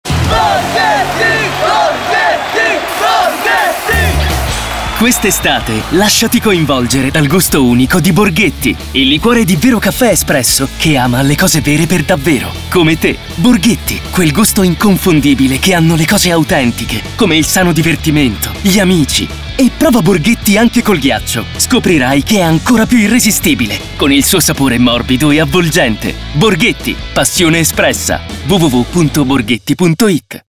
Interpretato